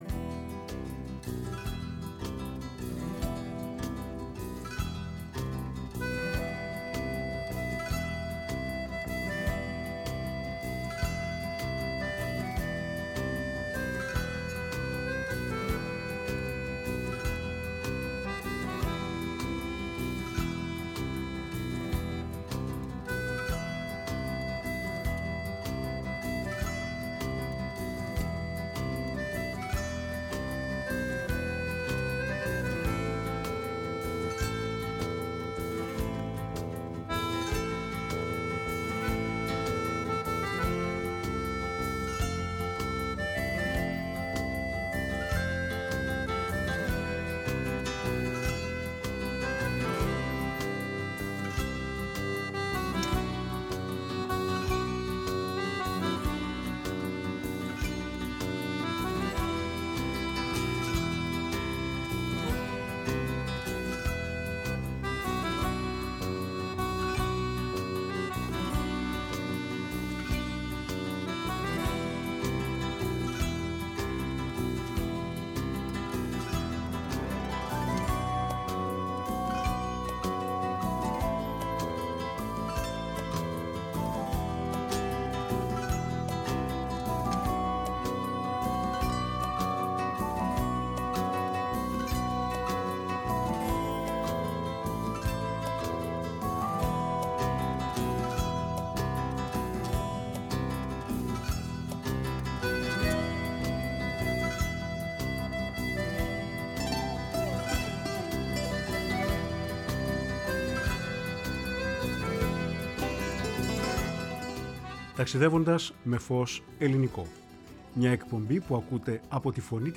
Ταξιδεύοντας με φως ελληνικό”, όπου παρουσίασε τραγούδια από το σύνολο της καλλιτεχνικής διαδρομής της, που περιλαμβάνει συνθέσεις από ροκ μέχρι παραδοσιακές!